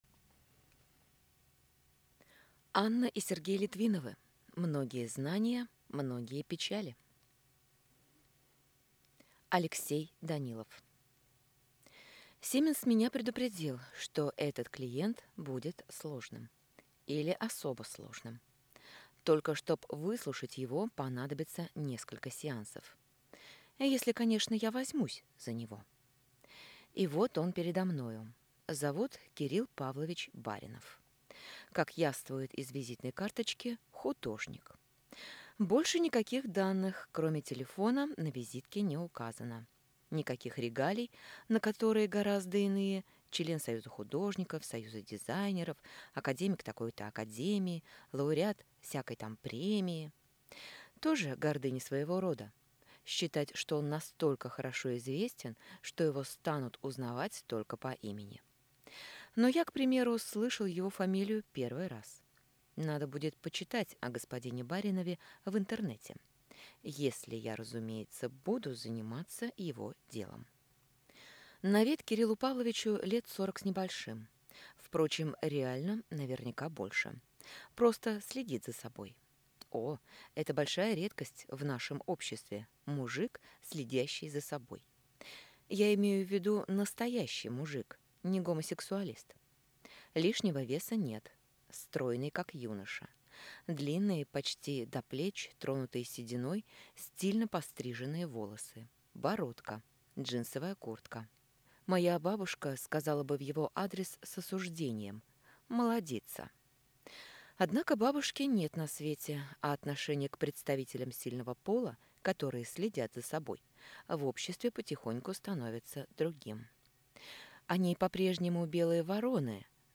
Аудиокнига Многие знания – многие печали | Библиотека аудиокниг